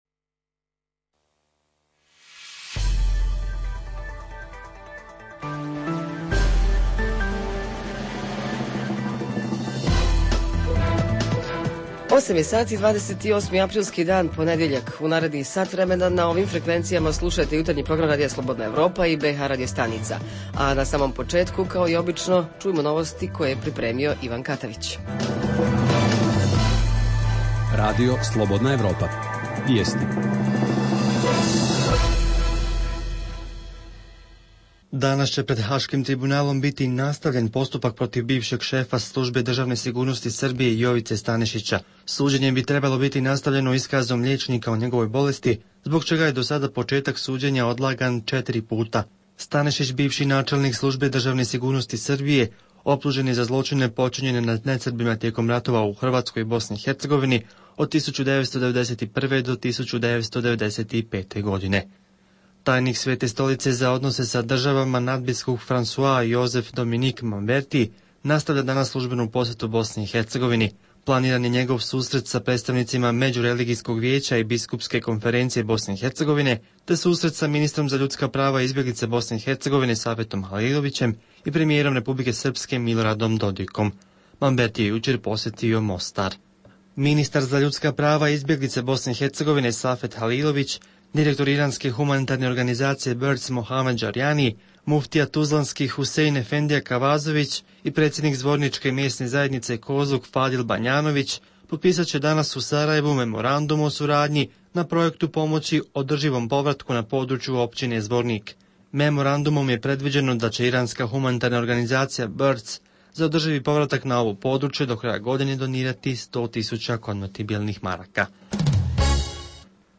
Jutarnji program za BiH koji se emituje uživo. Govorimo o najaktuelnijim i najzanimljivijim događajima proteklog vikenda.
Redovni sadržaji jutarnjeg programa za BiH su i vijesti i muzika.